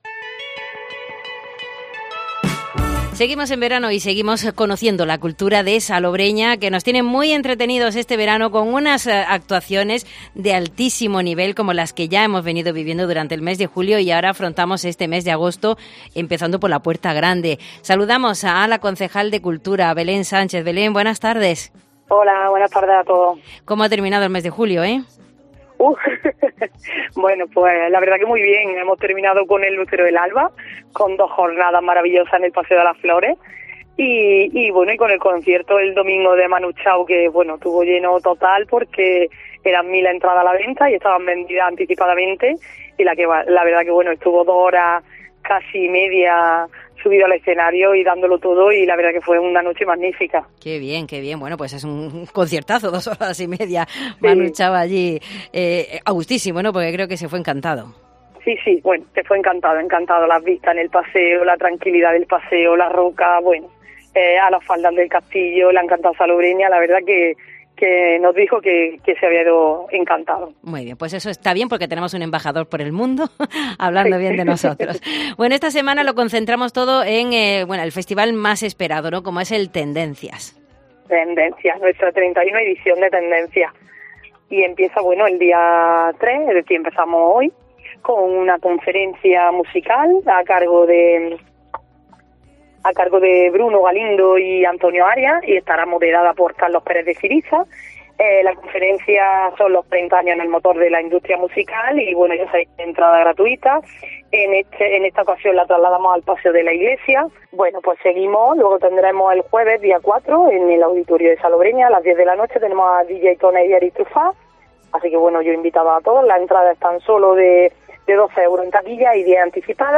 Conferencias y mucha música que nos comenta la concejal de Cultura Belén Sánchez